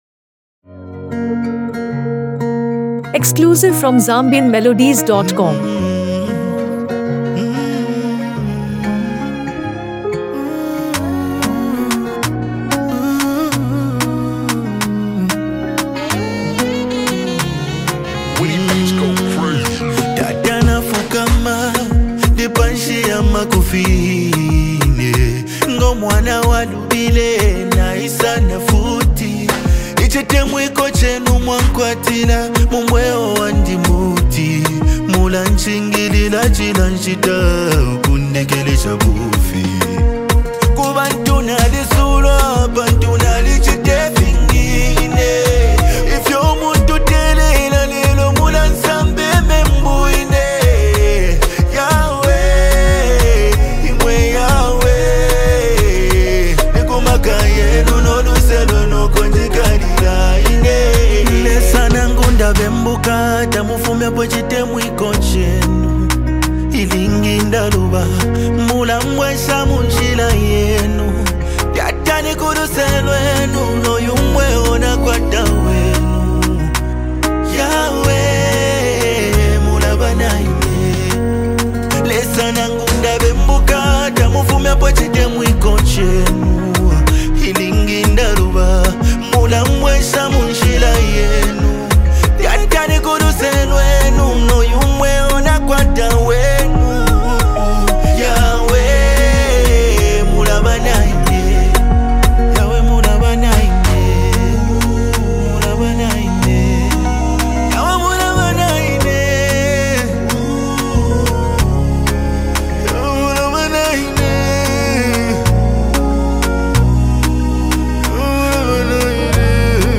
Zambian Music
brings his signature smooth vocals to this collaboration